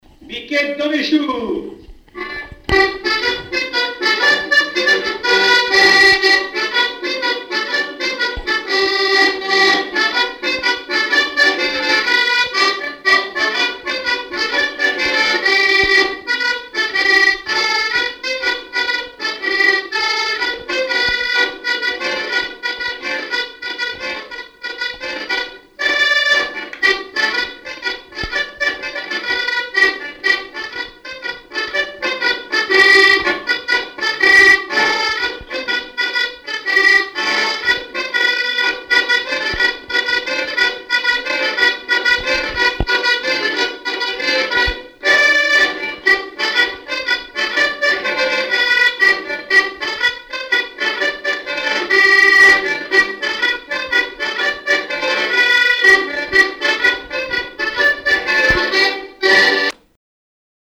danse : ronde
Genre énumérative
Catégorie Pièce musicale inédite